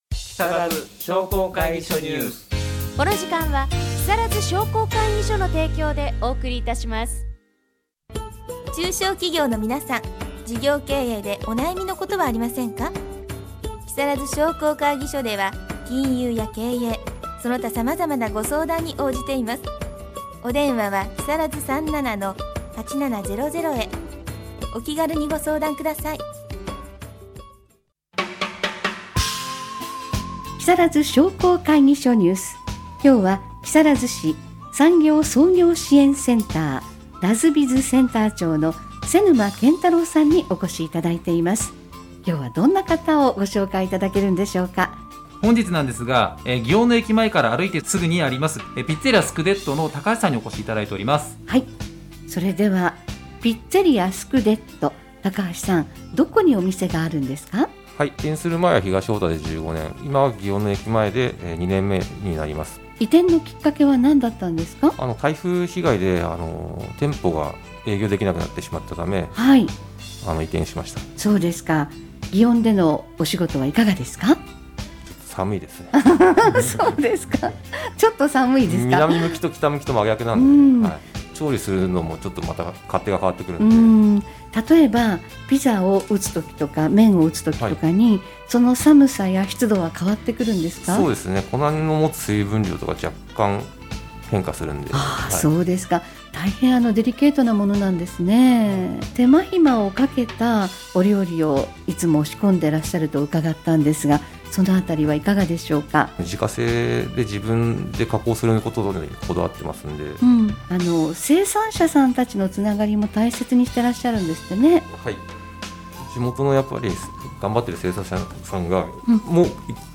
かずさエフエム「木更津商工会議所ニュース」 2/3放送分 出演：ピッツェリア・スクデット 様